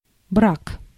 Ääntäminen
IPA: /brak/